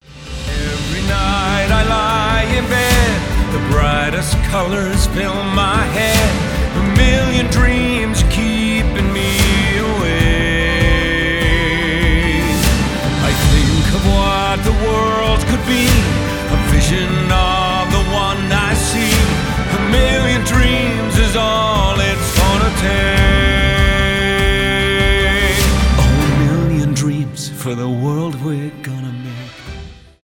мюзикл